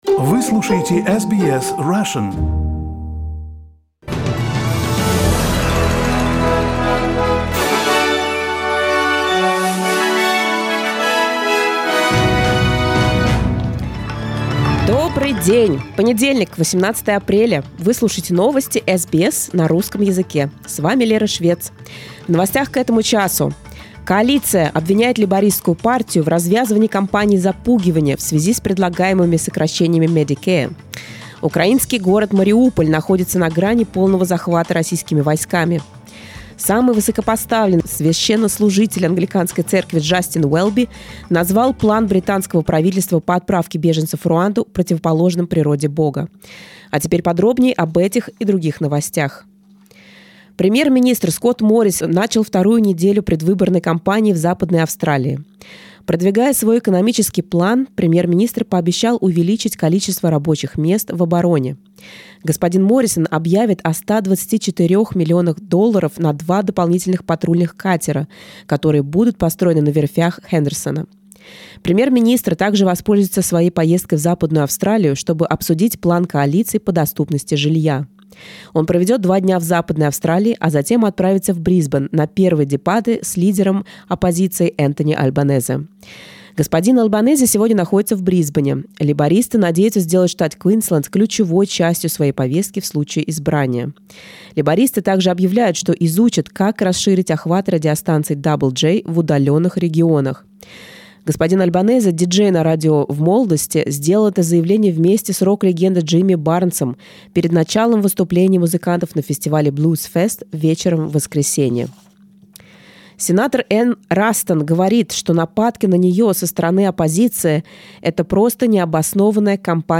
Новости SBS на русском языке — 18.04